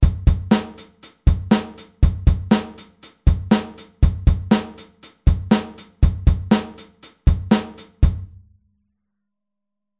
Als Veränderung zur vorherigen Variante wurde der rechte Fuß vom UND nach der Zählzeit 2 genau eine 4tel-Note nach hinten verschoben (technisch).
8telBeats05.mp3